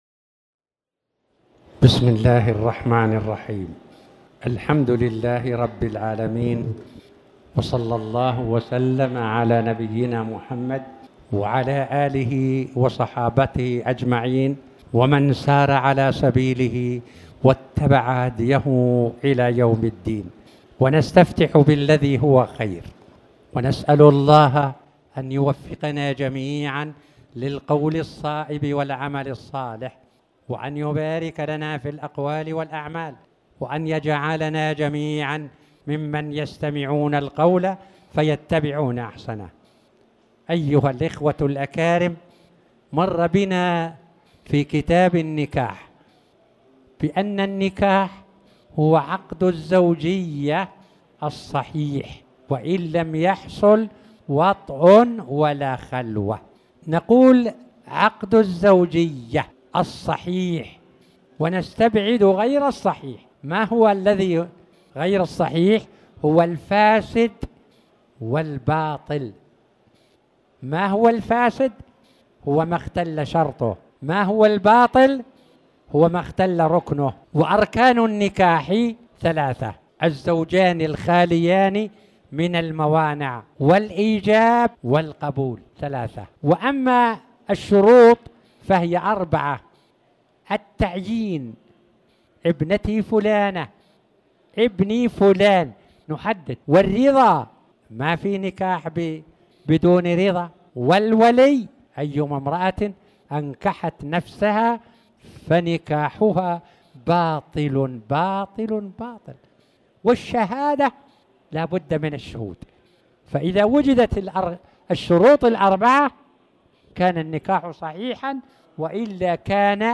تاريخ النشر ٤ جمادى الآخرة ١٤٣٩ هـ المكان: المسجد الحرام الشيخ